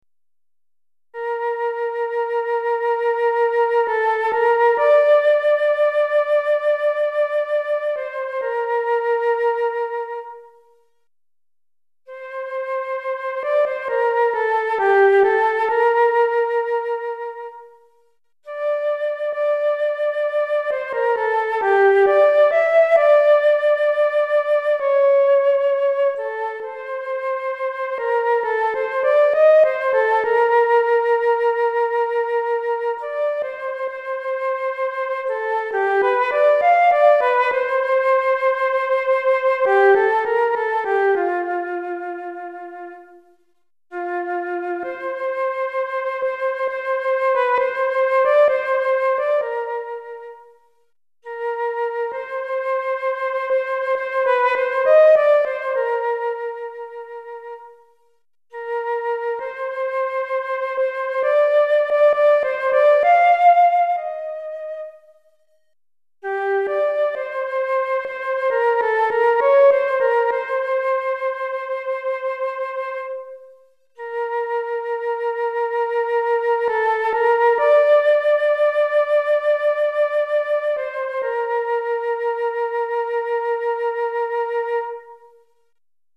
Flûte Traversière Solo